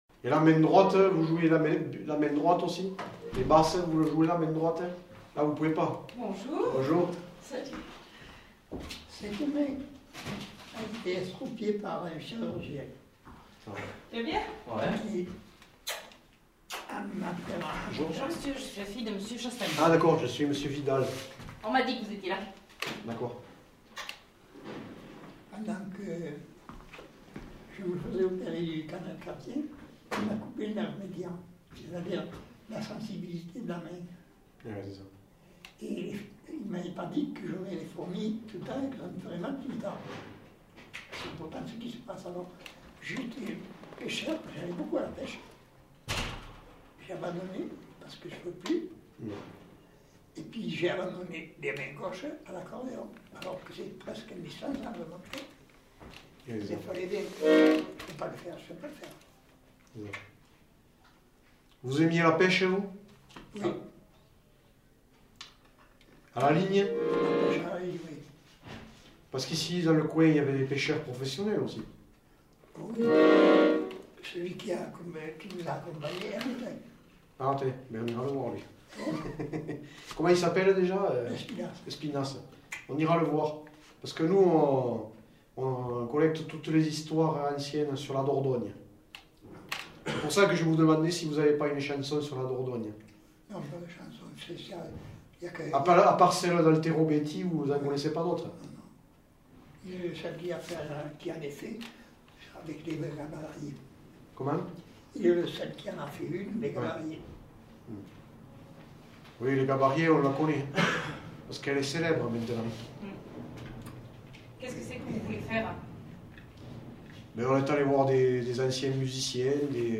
Valse